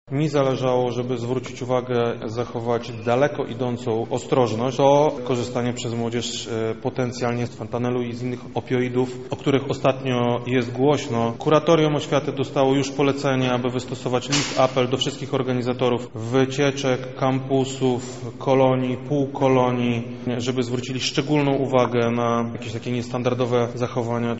Krzysztof Komorski -mówi wojewoda lubelski Krzysztof Komorski